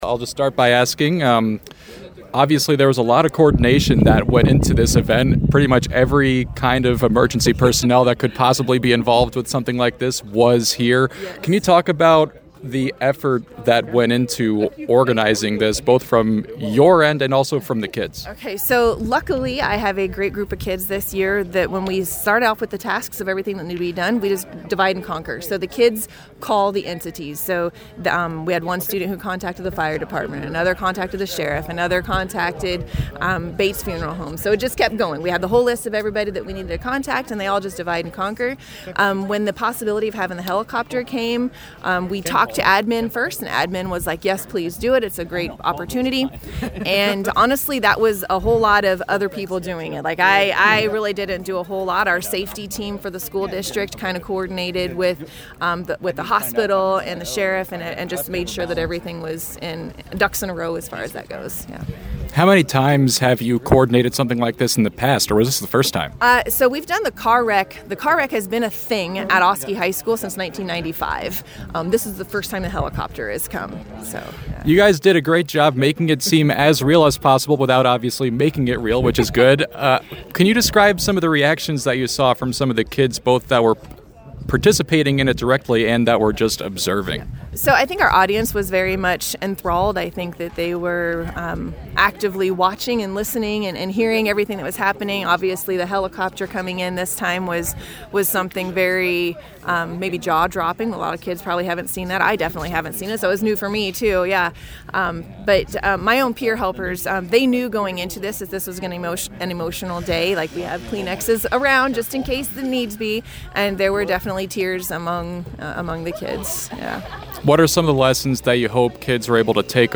Our full interview